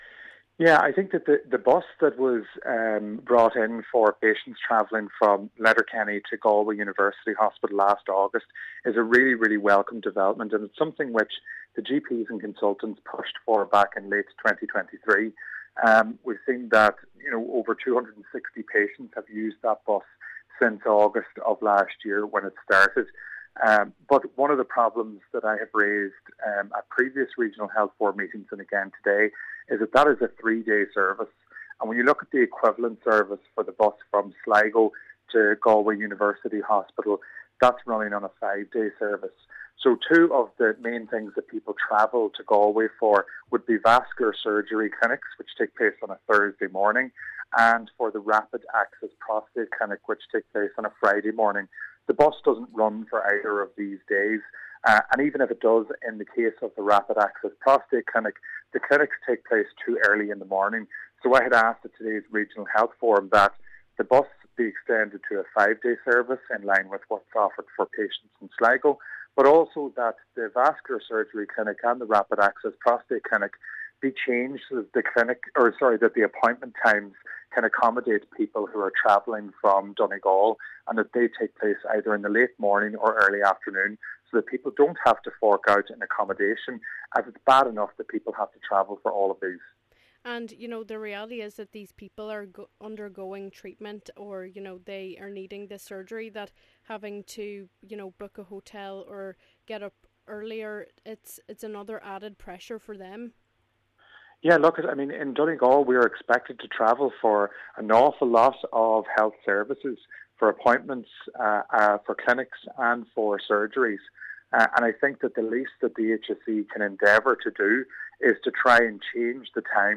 Cllr Meehan says the current schedule is not suitable for many of those who need it: